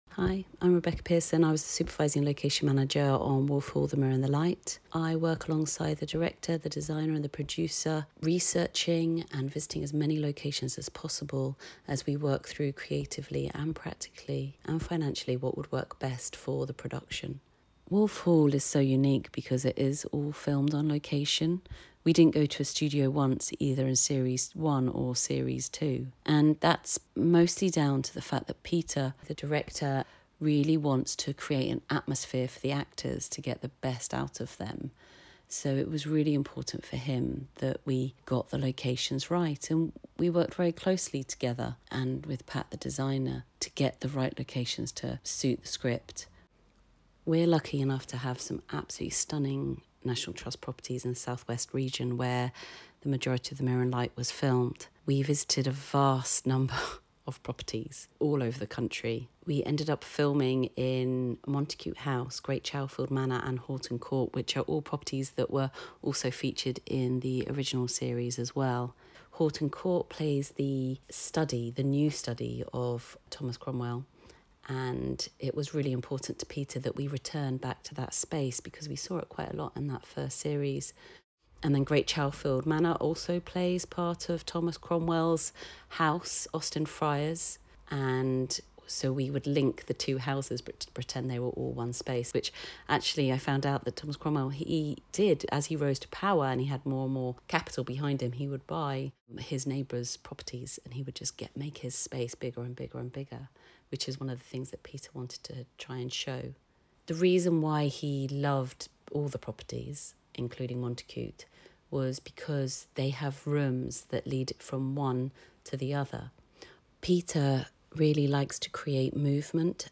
National Trust Magazine Spring 2025: The Mirror and The Light Interview